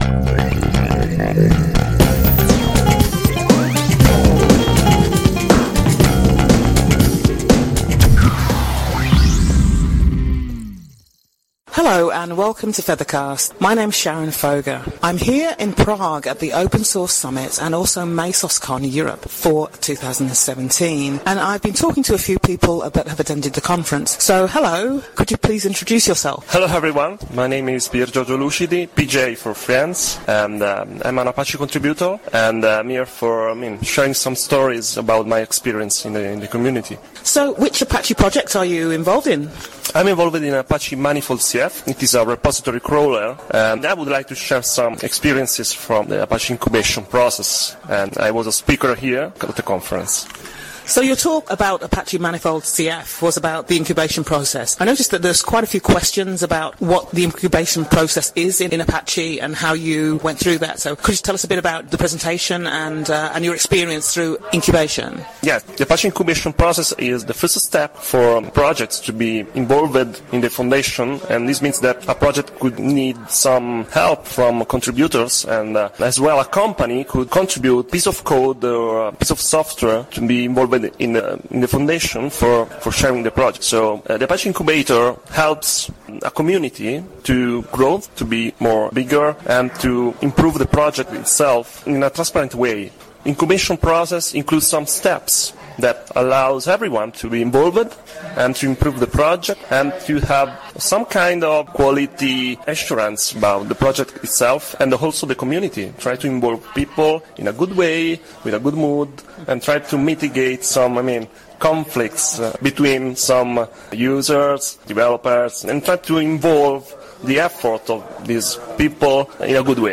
about Apache ManifoldCF, the Apache incubation process, his presentation  and a few other things at the Open Source Summit in Prague.